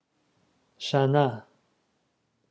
"shanah"